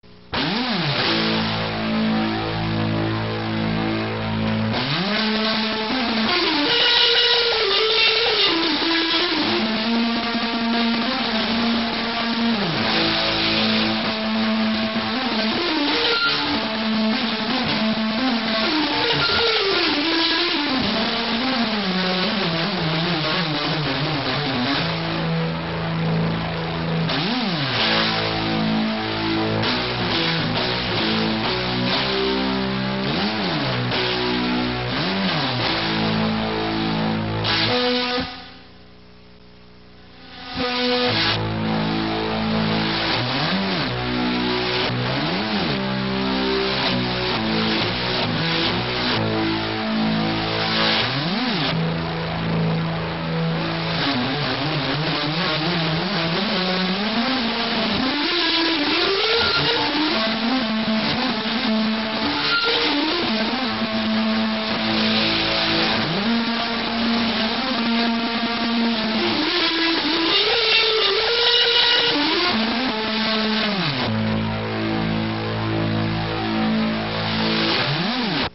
I play an old (1959) Fender Stratocaster that I bought used.
If you hear a guitar well... heu.. I played it on my strat.
Here's a jam solo that I did some time ago:
solo.mp2